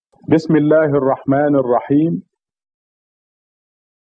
You can Listen to each verse of The Key (Al-Fatehah) being recited by clicking the "Listen" link next to the verse.